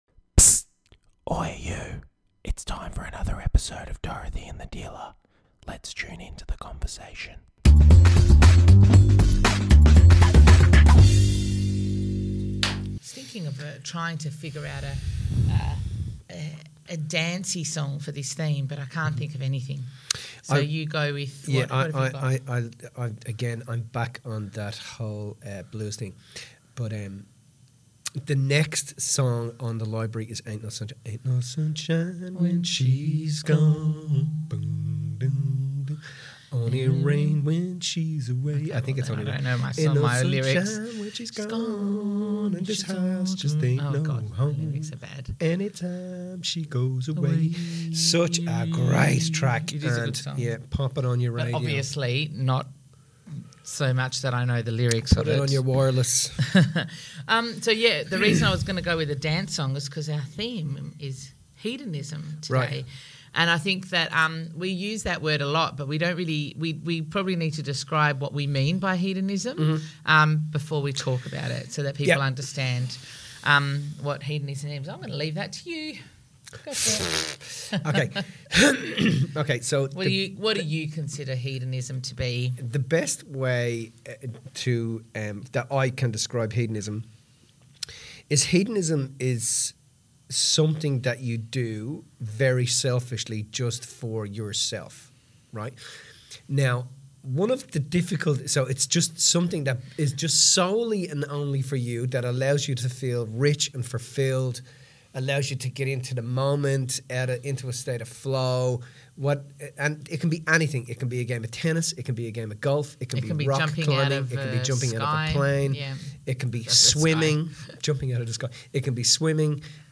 having a casual chat about what hedonism is all about